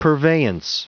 Prononciation du mot purveyance en anglais (fichier audio)
Prononciation du mot : purveyance